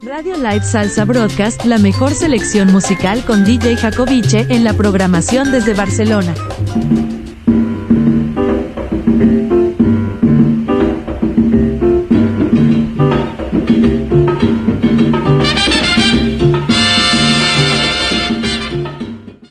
Musical
Indicatiu de la ràdio i tema musical